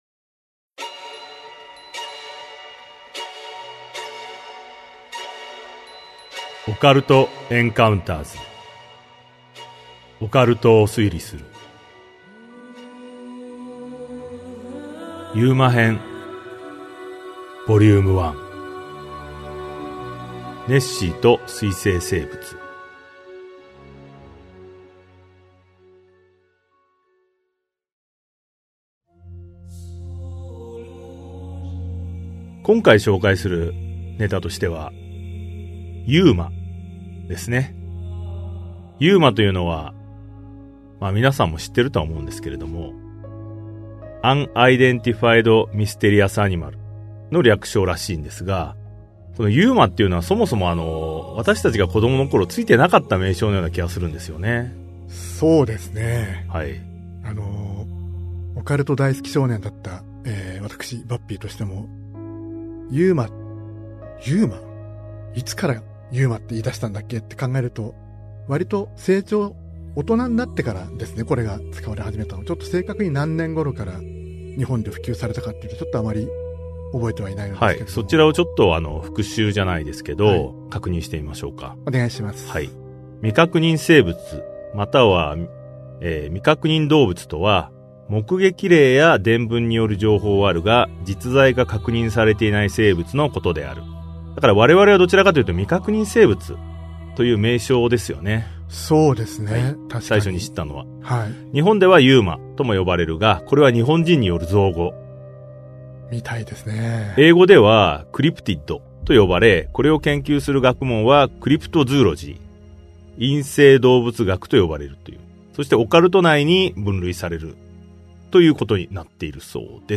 [オーディオブック] オカルト・エンカウンターズ オカルトを推理する Vol.07 UMA編1 ネッシーと水棲生物